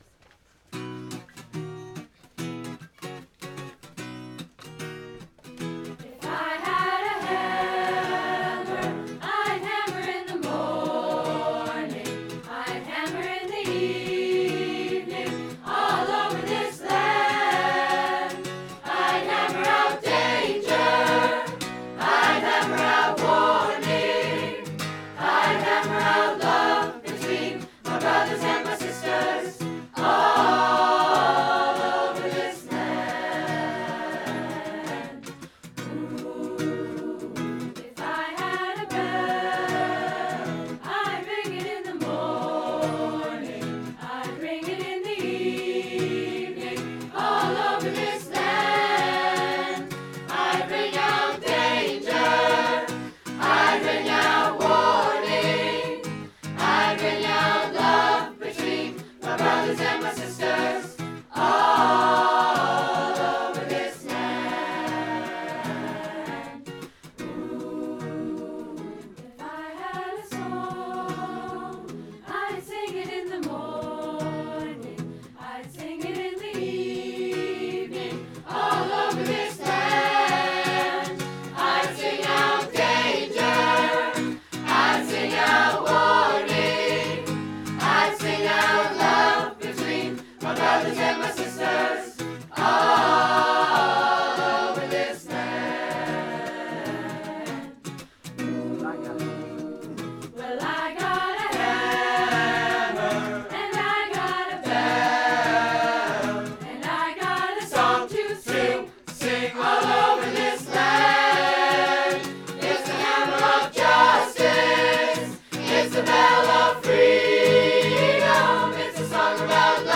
2:00 PM on July 20, 2014, "Music with a View"
Chorus